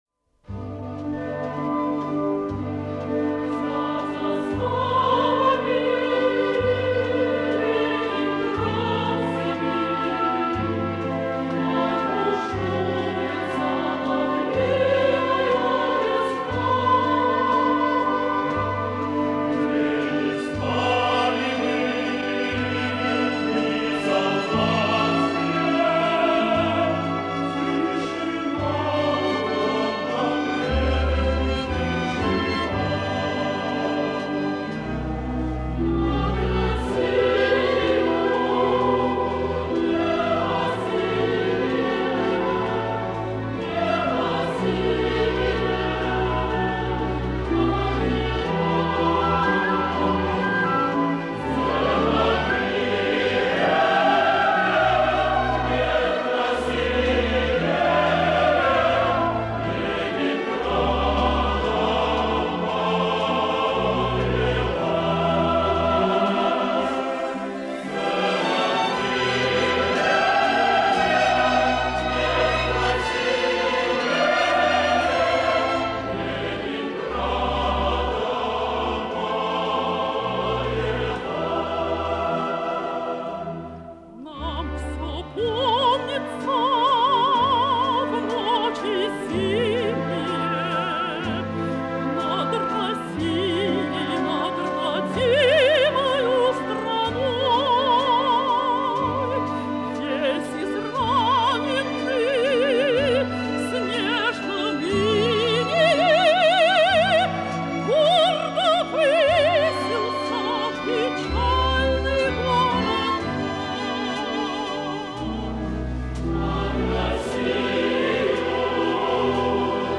Солистка